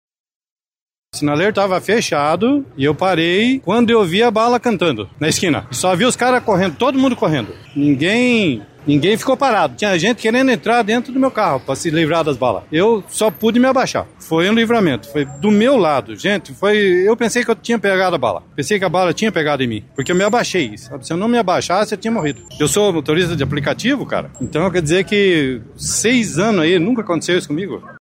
Um motorista de aplicativo, que passava pelo local, teve o carro atingido por um dos disparos e, por pouco, não foi baleado. Ele falou sobre o momento de tensão.